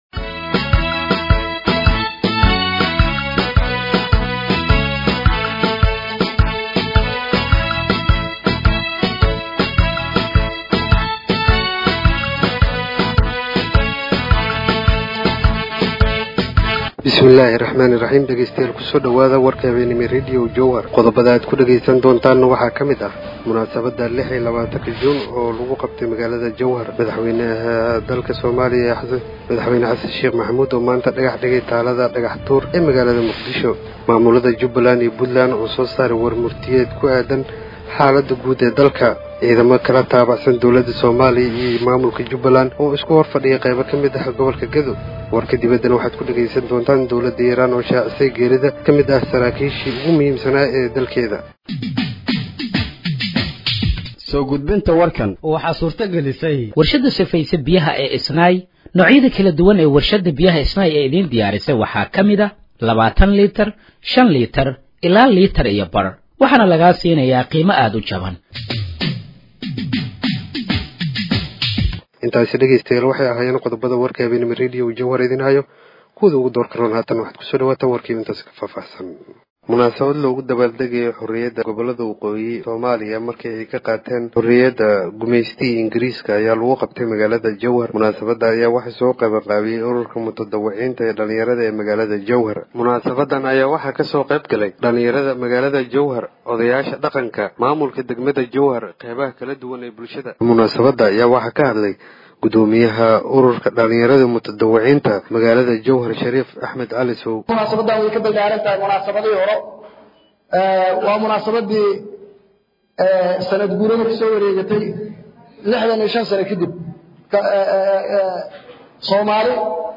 Dhageeyso Warka Habeenimo ee Radiojowhar 26/06/2025
Halkaan Hoose ka Dhageeyso Warka Habeenimo ee Radiojowhar